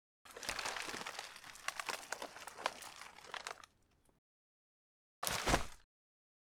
段ボールをひっぺがす
段ボールをひっぺがすV2.wav